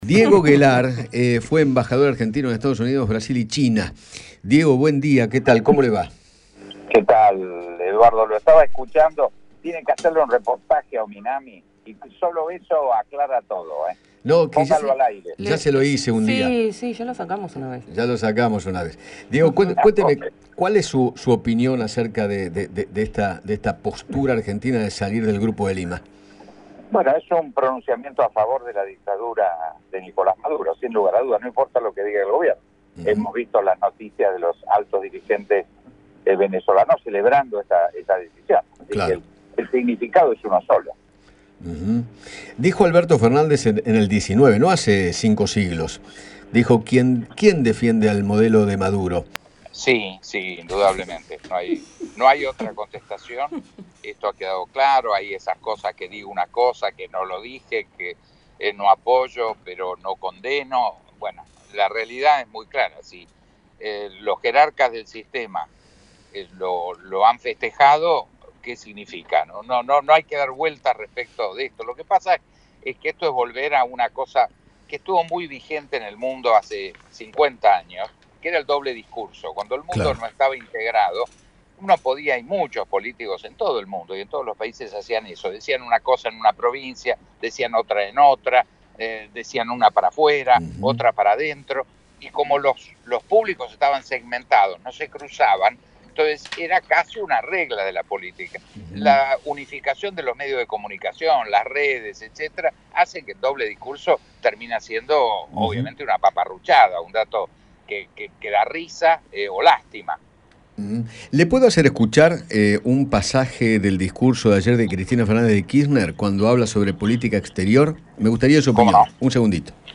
Diego Guelar, ex embajador en China, dialogó con Eduardo Feinmann sobre la decisión de Alberto Fernández de abandonar el Grupo Lima, la institución constituida por países de la región cuya misión es liberar a Venezuela de la crisis social, humanitaria y económica.